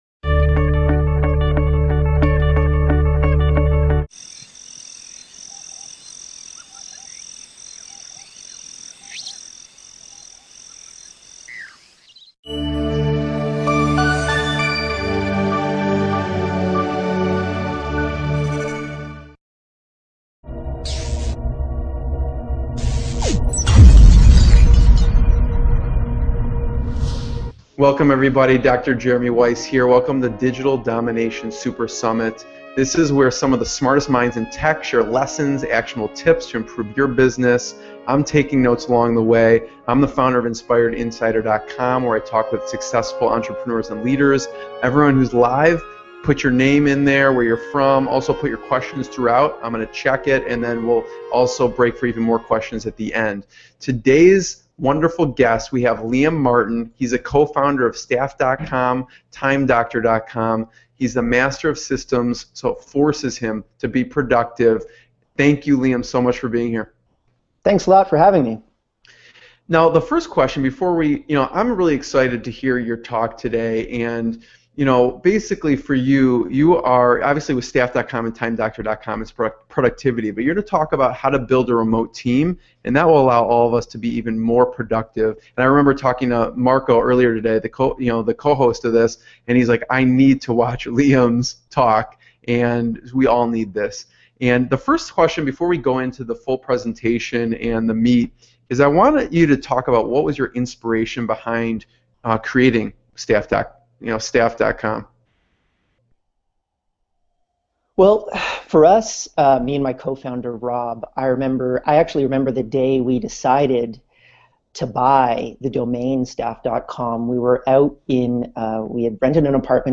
Presentation: How To Build A Remote Team: Finding People so you can save time, energy, and money Big Promise: You will learn how to find and hire remote staff to build your business.